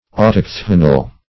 Autochthonal \Au*toch"tho*nal\, Authochthonic